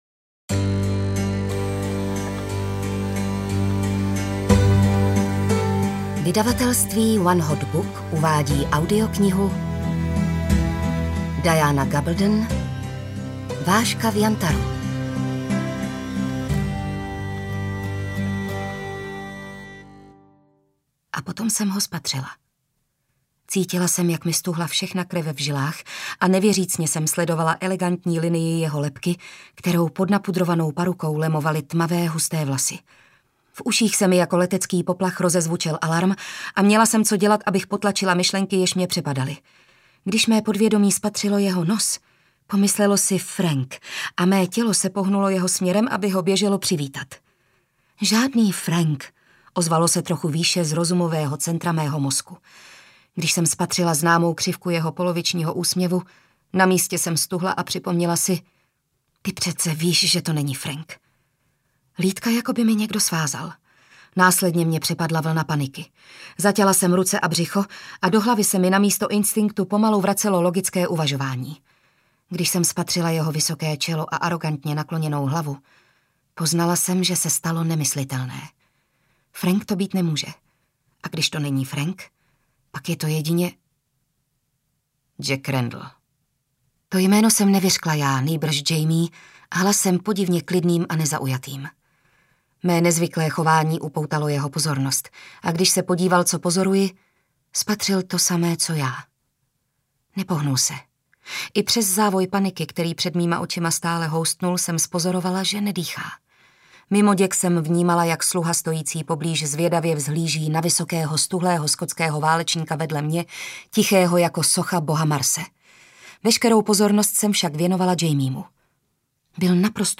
Vážka v jantaru audiokniha
Ukázka z knihy